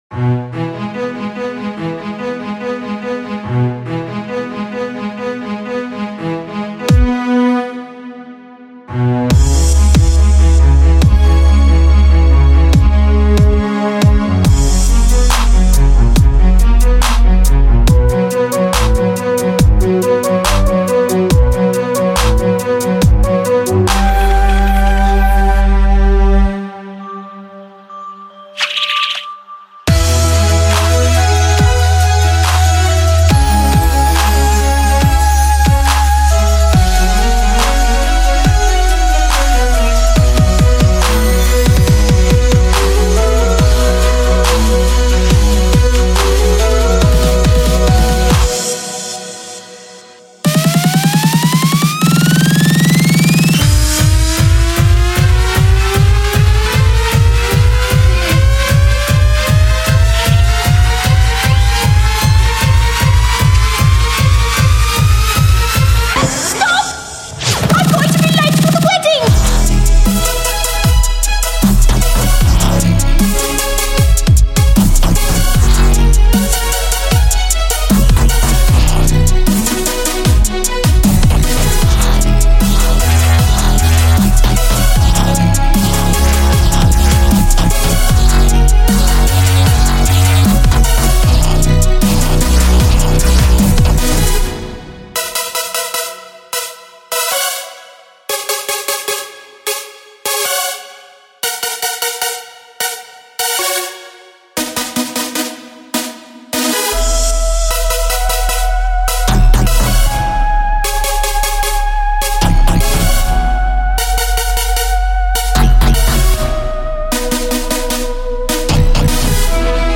Wedding duet remix!
genre:remix